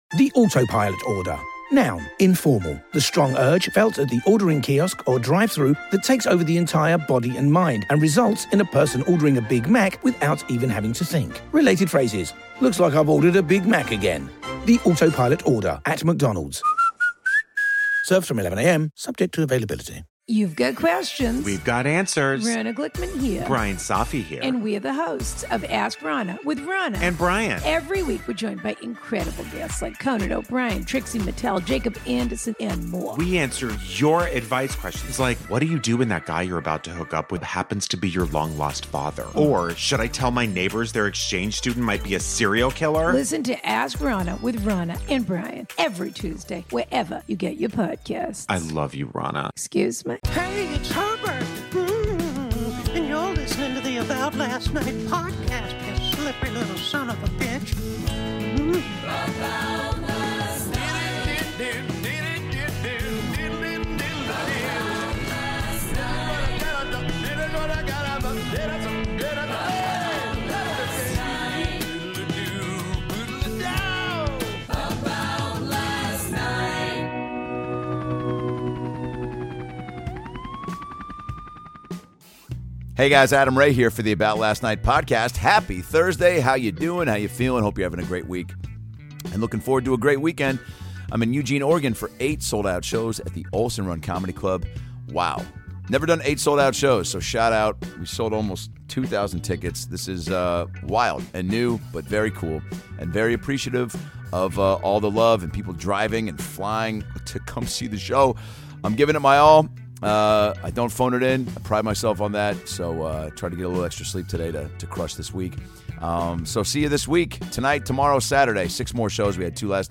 #809-Dr. Phil: LIVE! With Harland Williams Live from Toronto
PART 2 of "Dr. Phil Live" from Toronto with Harland Williams!